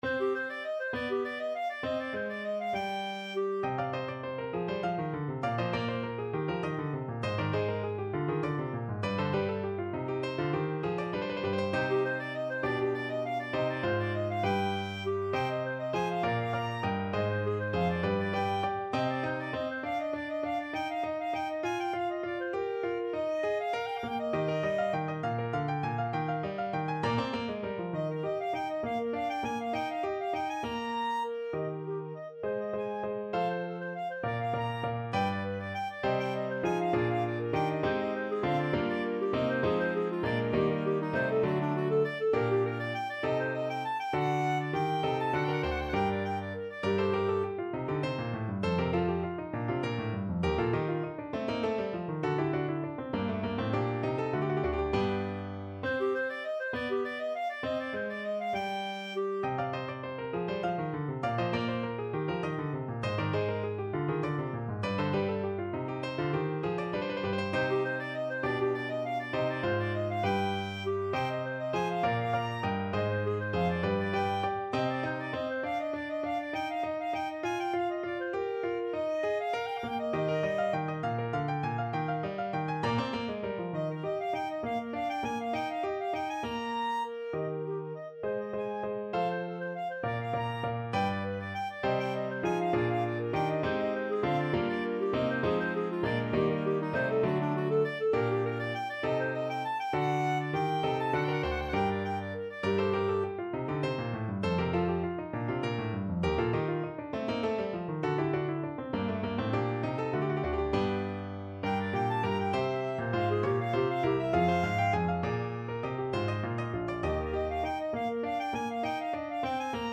Free Sheet music for Clarinet
Clarinet
3/8 (View more 3/8 Music)
D5-Bb6
Classical (View more Classical Clarinet Music)
marcello_concerto_3_CL.mp3